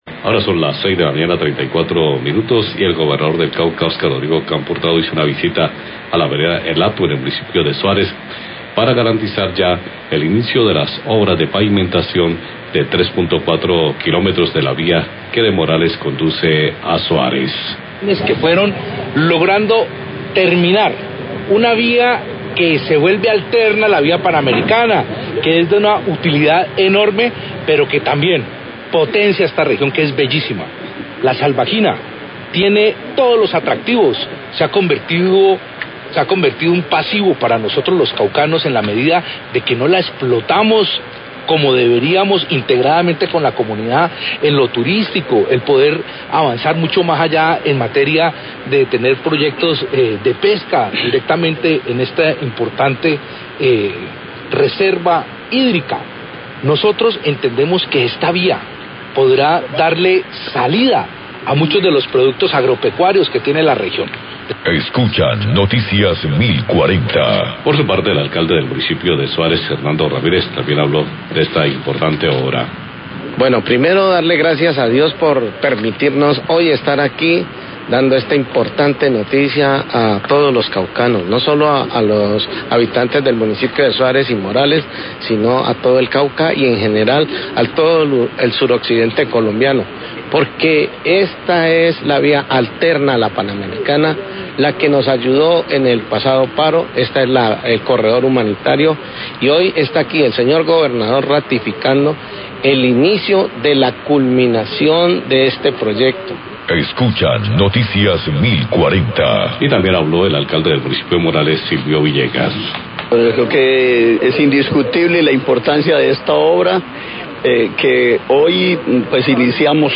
Radio
Gobernador del Cauca atendió una visita en la vereda Hato, Suárez, para socializar el inicio de la obra de pavimentación de la vía Morales - Suárez, se beneficiará la economía y el turismo hacia La Salvajina. declaraciones del Gobernador del Cauca, Oscar Campo; Alcalde de Suárez, Hernando Ramirez y del Alcalde de Morales, Silvio Villegas.